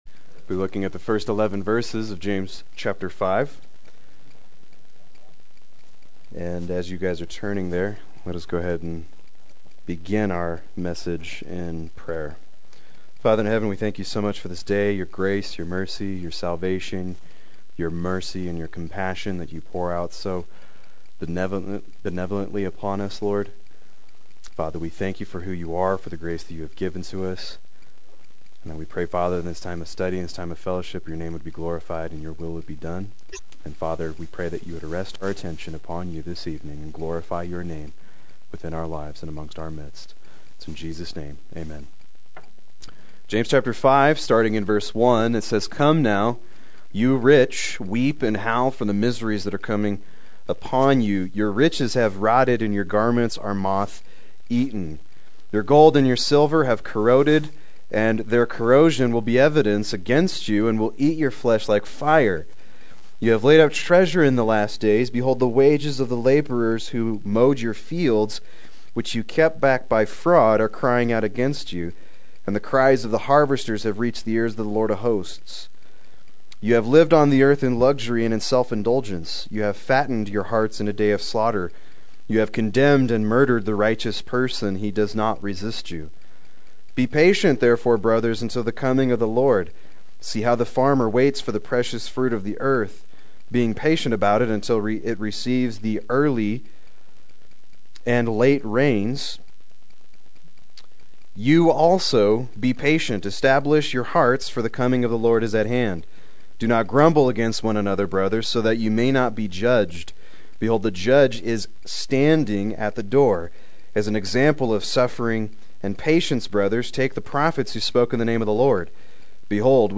Proclaim Youth Ministry - 11/14/15
Play Sermon Get HCF Teaching Automatically.